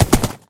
gallop3.mp3